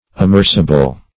Amerceable \A*merce"a*ble\, a. Liable to be amerced.